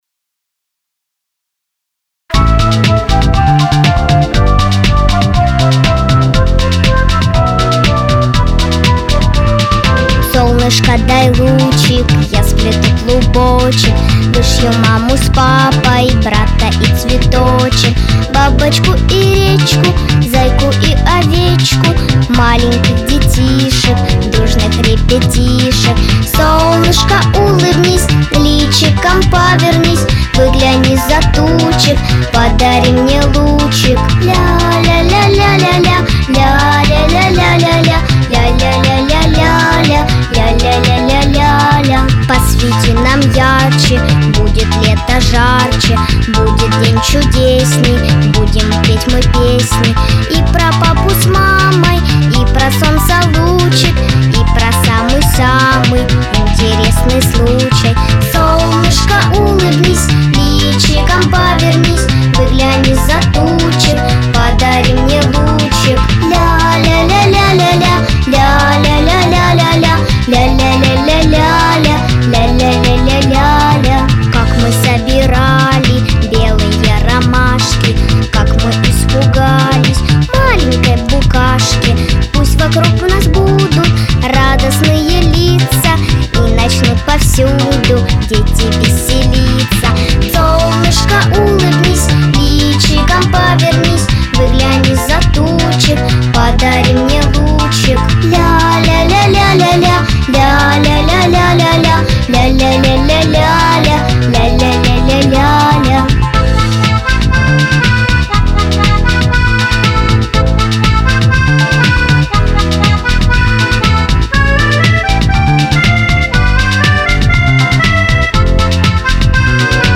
• Песня: Детская